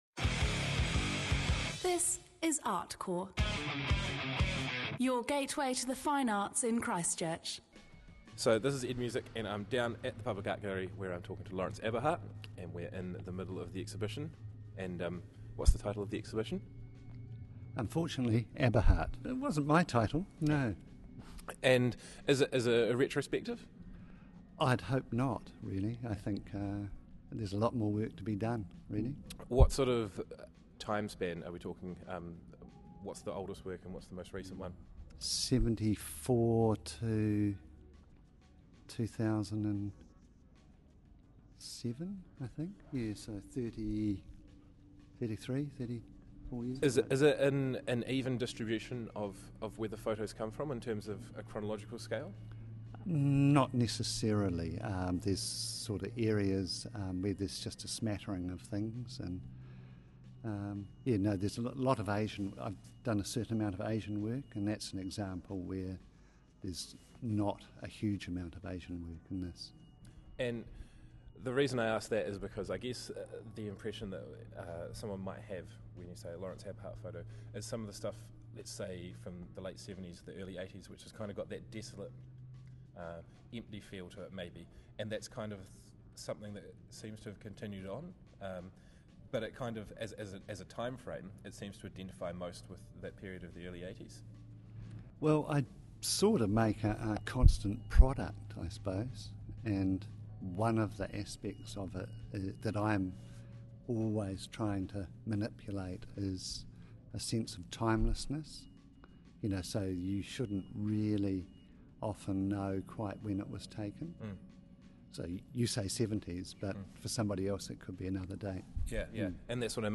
Listen to an interview with Laurence Aberhart discussing his 2008 exhibition Laurence Aberhart.
InterviewWithLaurenceAberhart.mp3